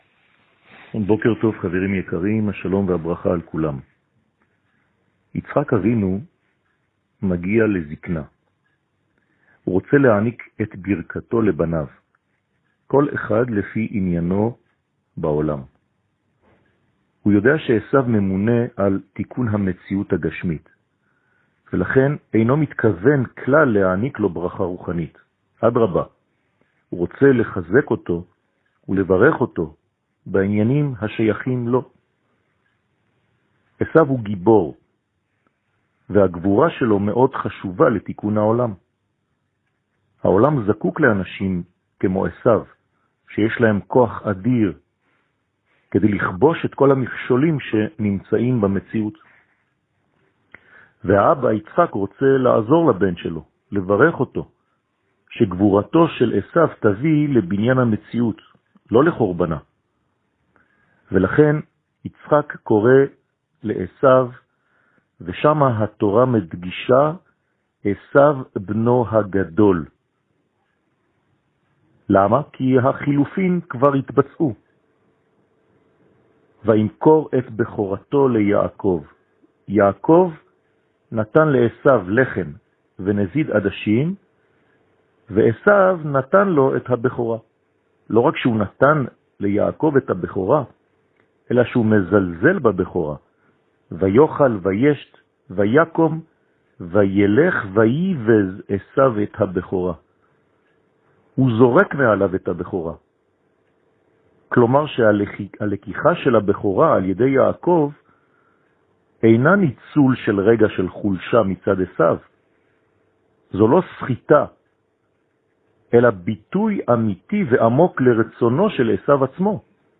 שיעור מ 19 נובמבר 2020